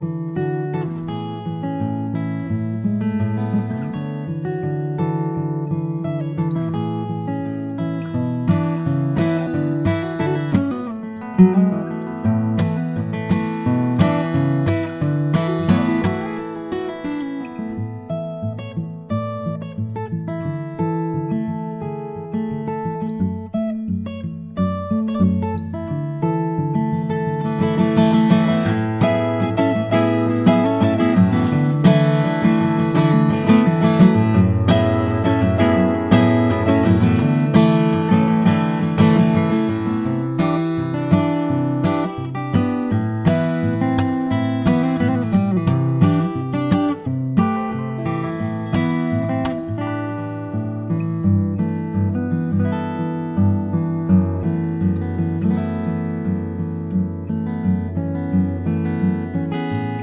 • Sachgebiet: Liedermacher